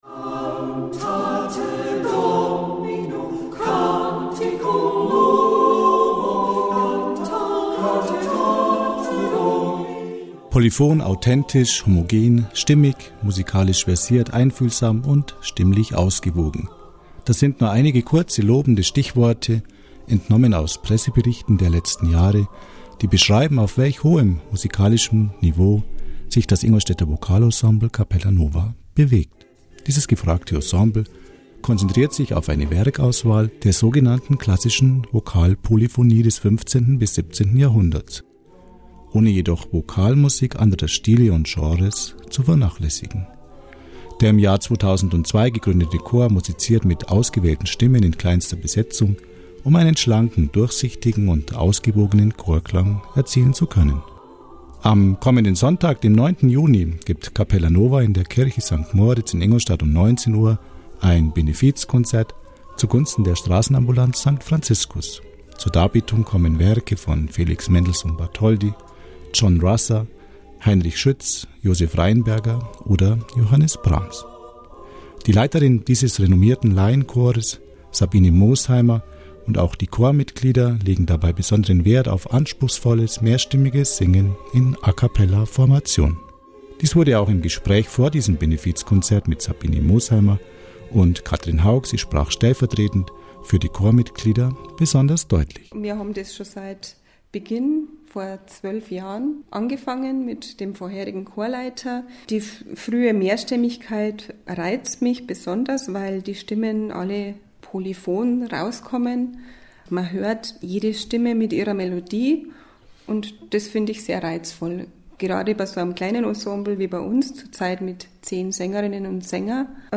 Kulturkanal Ingolstadt vom 07.06.2013 Polyfon, authentisch, homogen, stimmig, musikalisch versiert, einf�hlsam und stimmlich ausgewogen...
Radiobericht